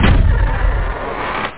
DOORWOOD.mp3